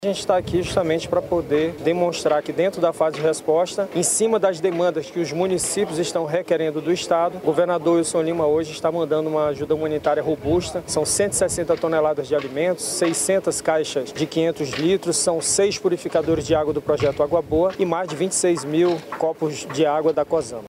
O secretário da Defesa Civil do Amazonas, coronel Francisco Máximo, explica que as três cidades atendidas, neste momento, estão sob decretos de Situação de Emergência.